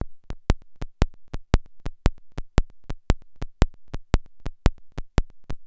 57-OSC.CLI-R.wav